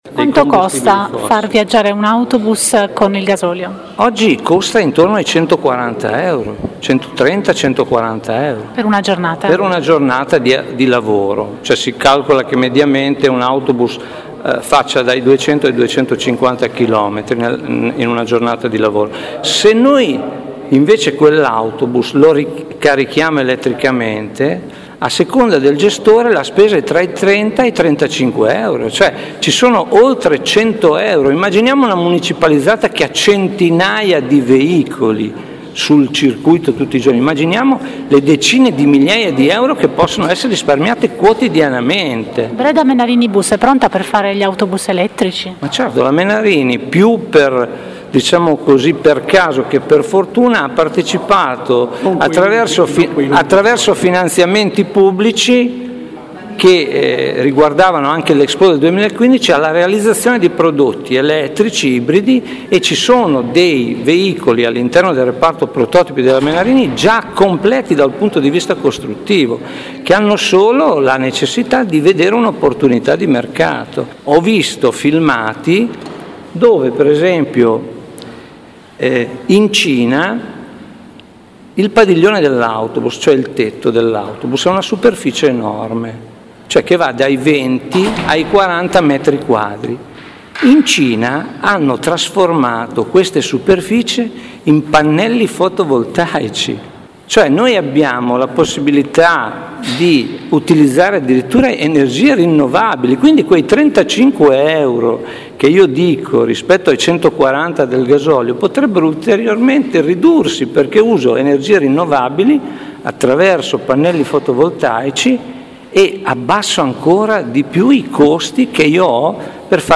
operaio e Rsu Fiom